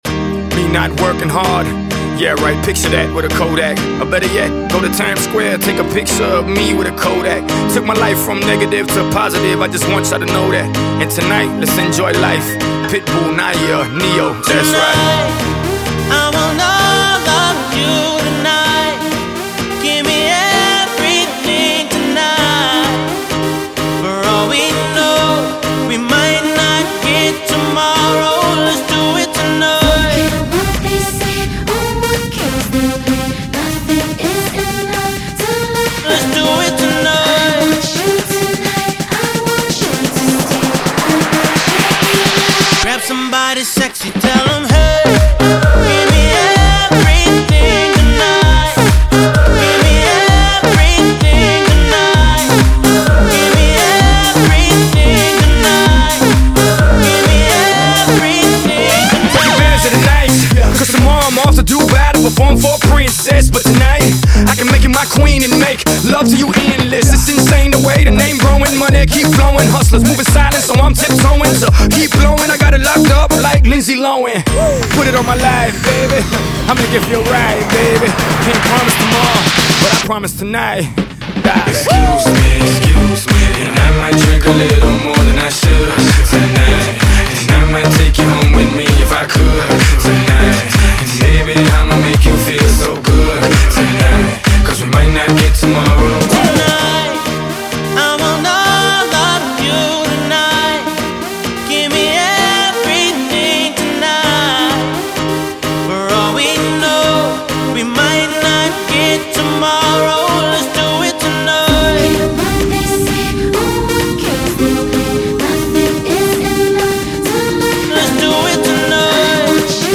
〽 ژانر Dance Pop